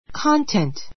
kɑ́ntent